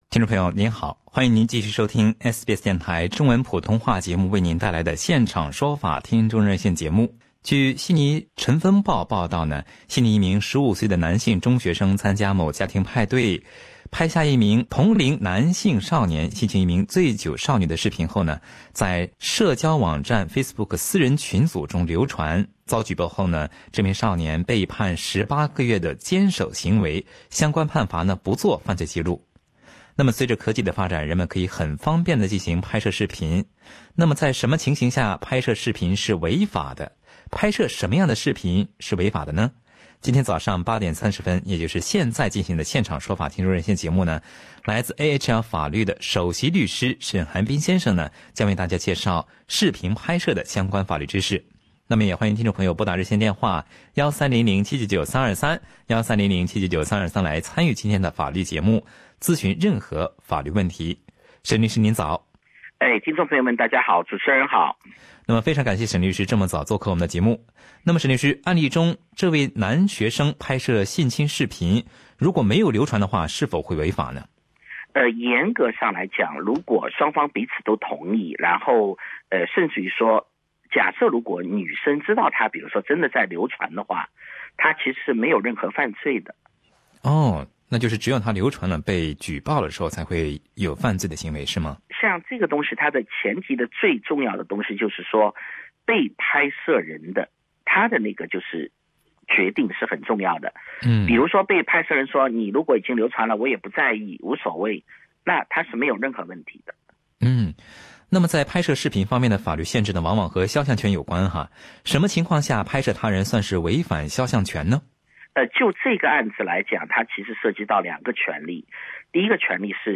听众热线节目
另外，听众还在节目中进行了法律咨询，以下是其中的两条咨询内容简介。